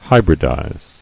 [hy·brid·ize]